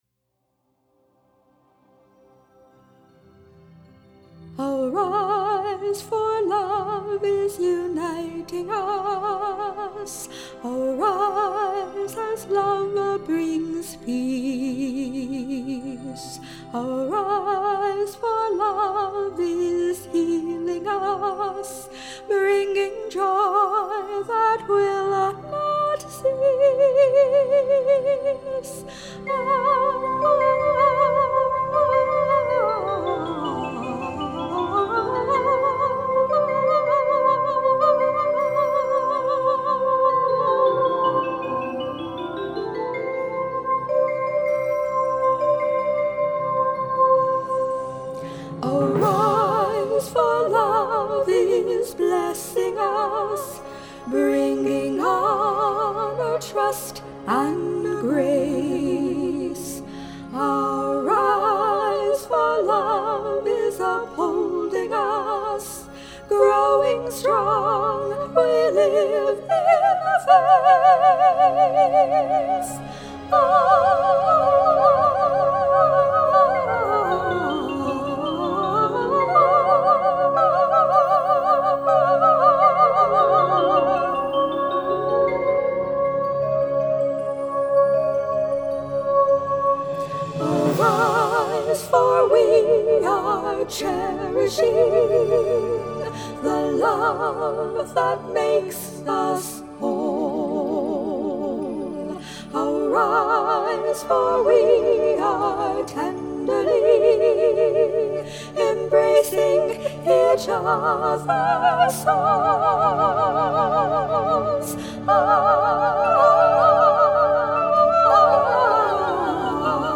Soundscape